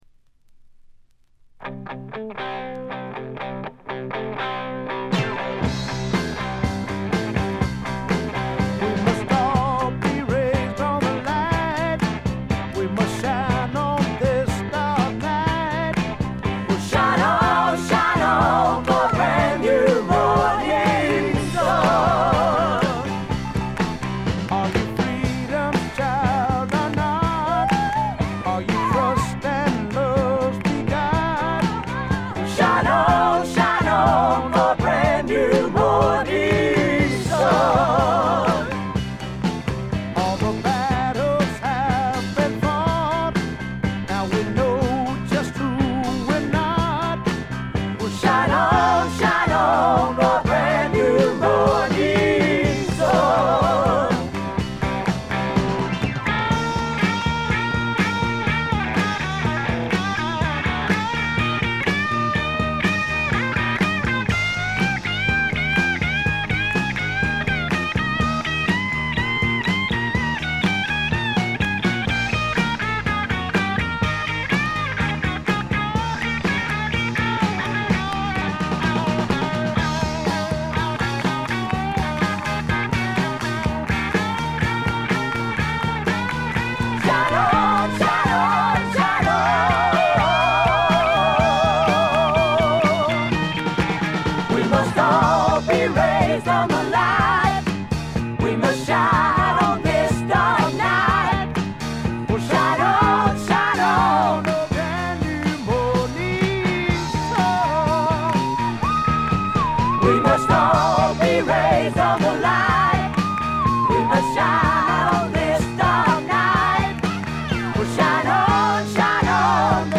A面冒頭少しチリプチ。
スワンプ基本盤。
試聴曲は現品からの取り込み音源です。